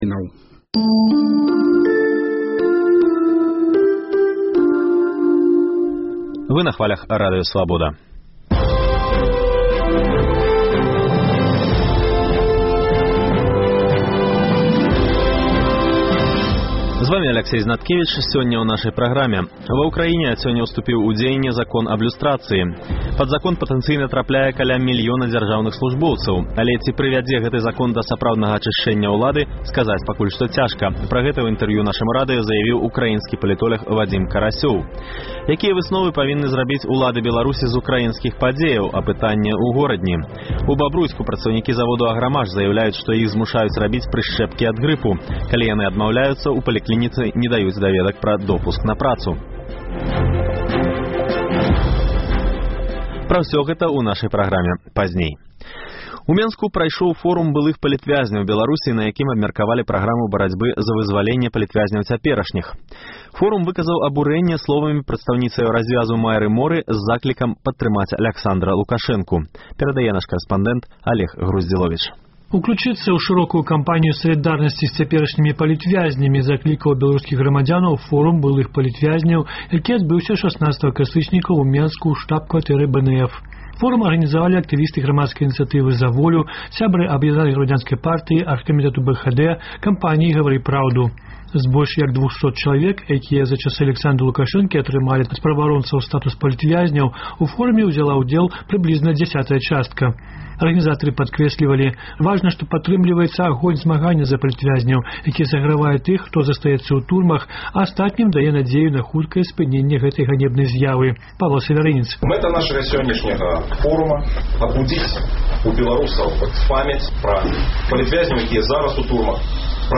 Апытаньне ў Горадні У Бабруйску працаўнікі заводу «Аграмаш» заяўляюць, што іх змушаюць рабіць прышчэпкі ад грыпу.